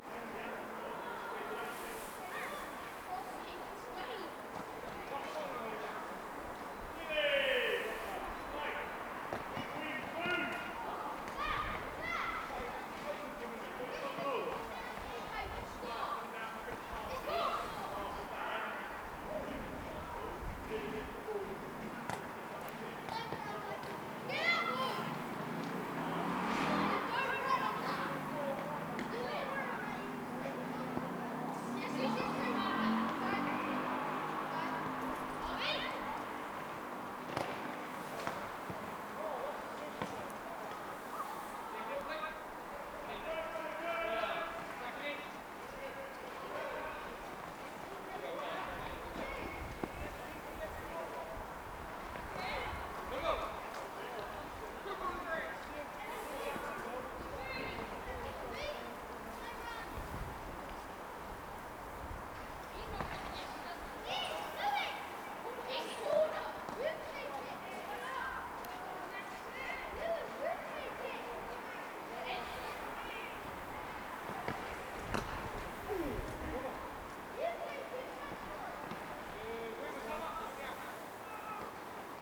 Threads of noise, tape loops, and samples.
There’s a church, and a steeple, and all the people are in the school next door’s sports yard kicking a ball around.